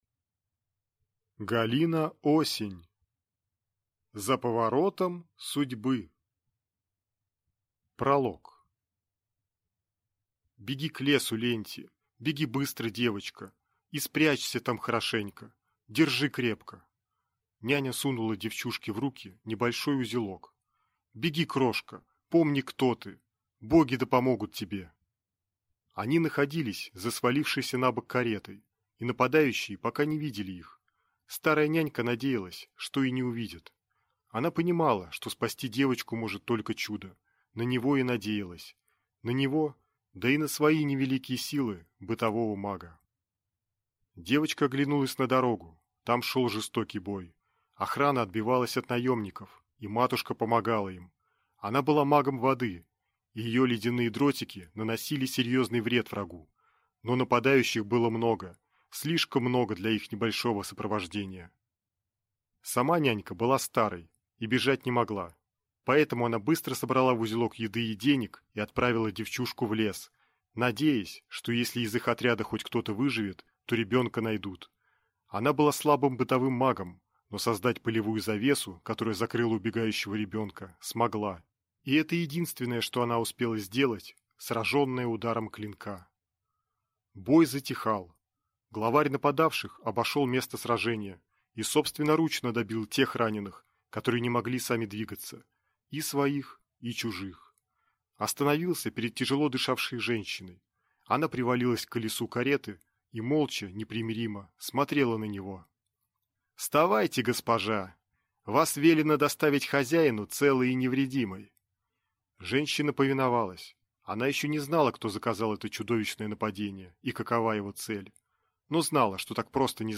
Аудиокнига За поворотом судьбы | Библиотека аудиокниг